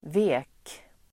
Uttal: [ve:k]